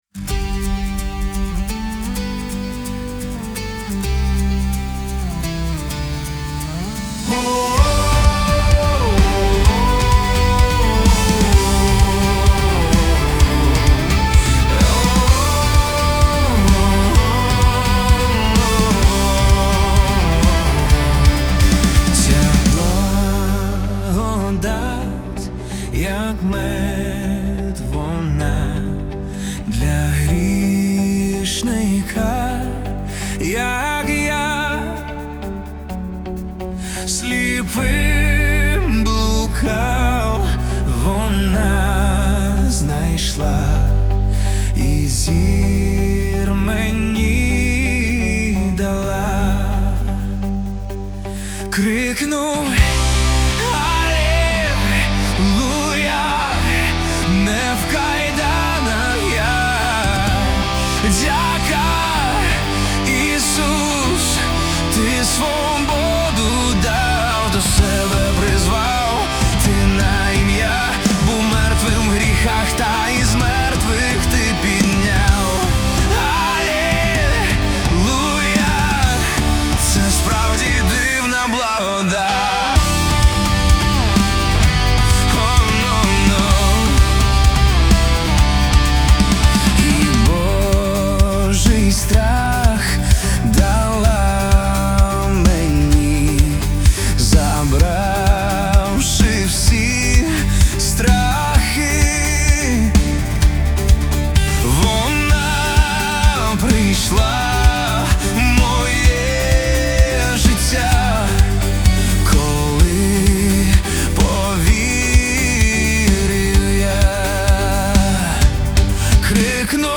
песня ai
138 просмотров 26 прослушиваний 2 скачивания BPM: 128